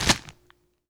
Crunch8.wav